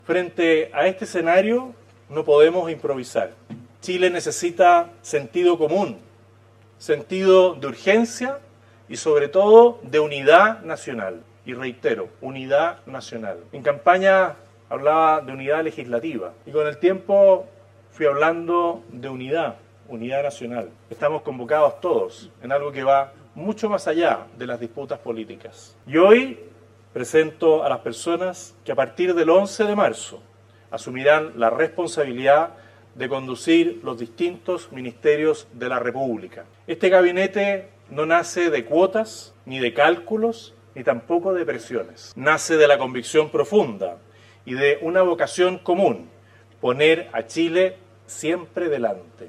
En una ceremonia realizada en la Oficina del Presidente Electo (OPE), ubicada en la comuna de Las Condes y conocida popularmente como la “Moneda Chica”, el presidente electo José Antonio Kast dio a conocer este martes a las y los ministros que integrarán su gabinete, de cara al gobierno que asumirá oficialmente el próximo 11 de marzo.